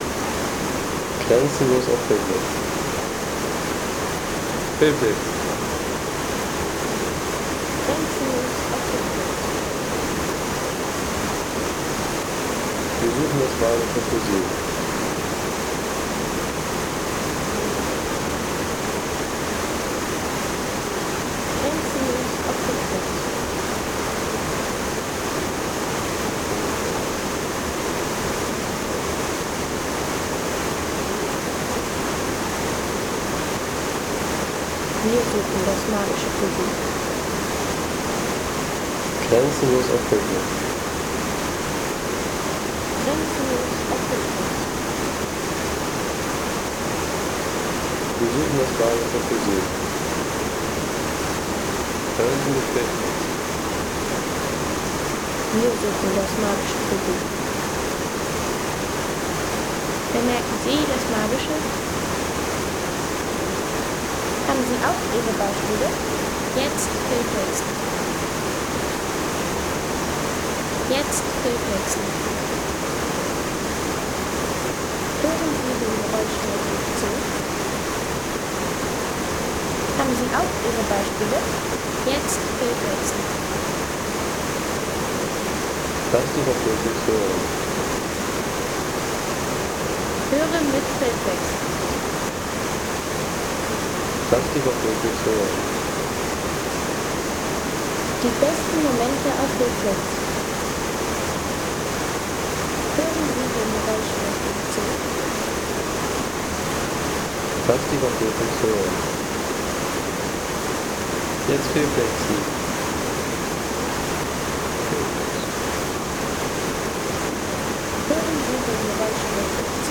Landschaft - Wasserfälle